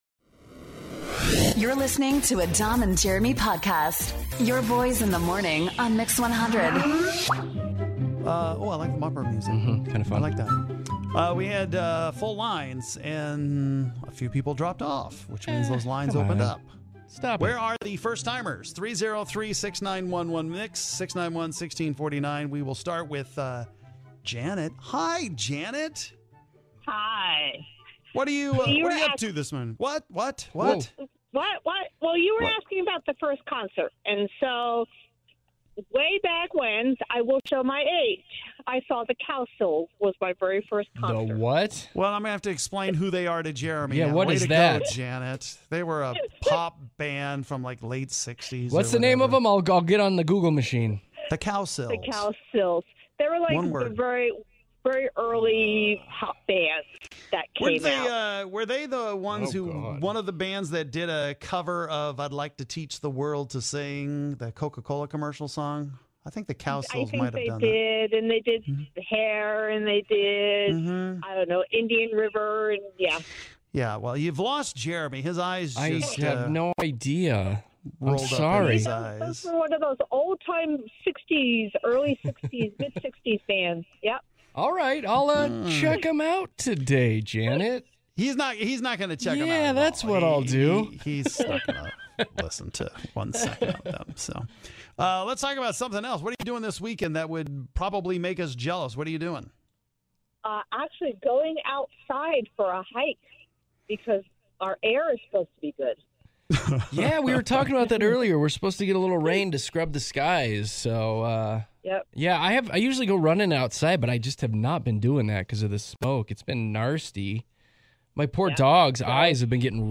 We talk to our longtime listeners for the first time.